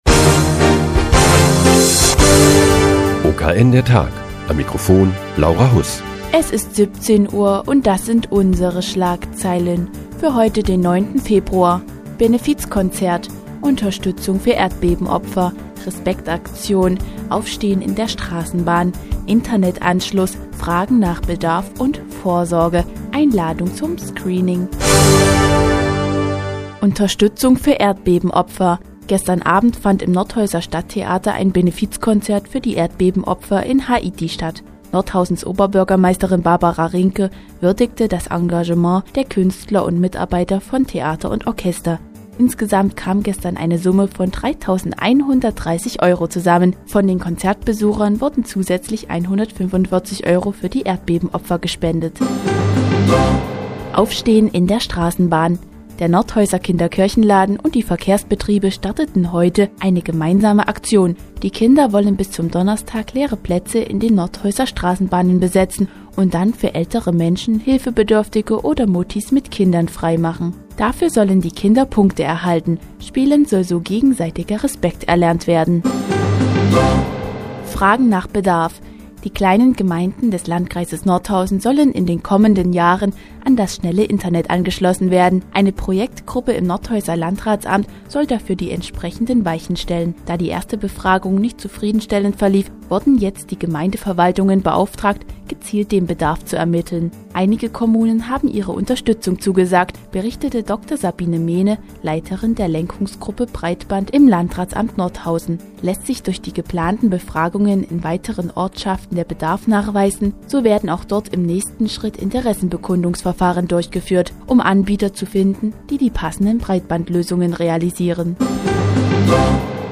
Die tägliche Nachrichtensendung des OKN ist nun auch in der nnz zu hören. Heute geht es um die Lenkungsgruppe Breitband im Nordhäuser Landratsamt und das im Juni stattfindende Brustkrebs-Screeningprogramm.